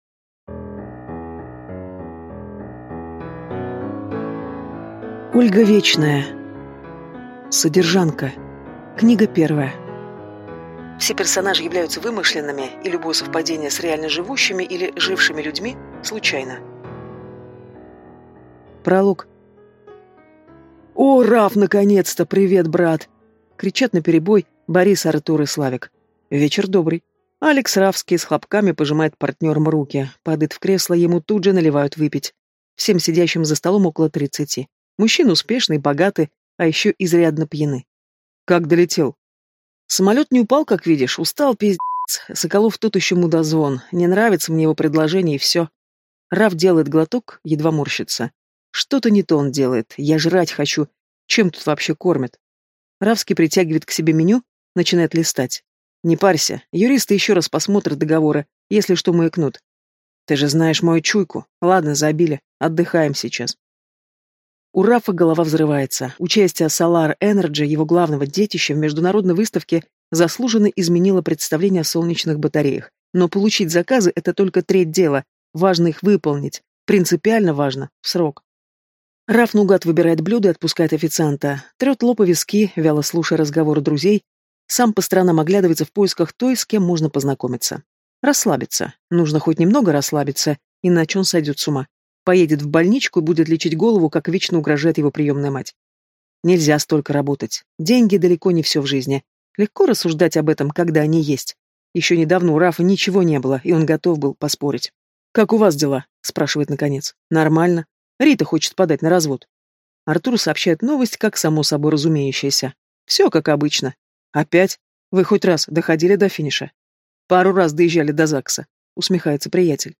Аудиокнига Содержанка. Книга 1 | Библиотека аудиокниг